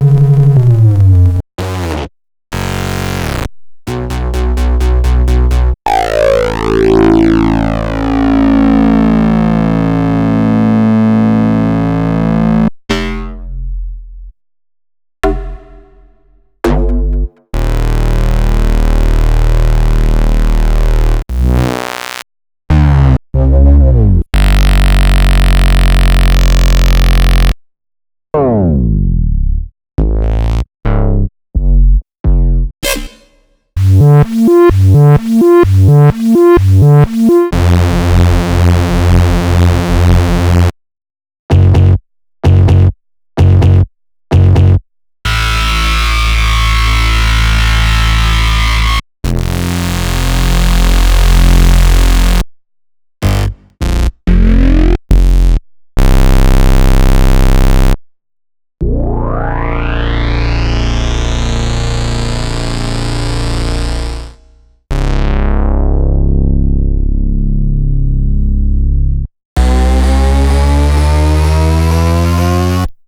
BASS0.wav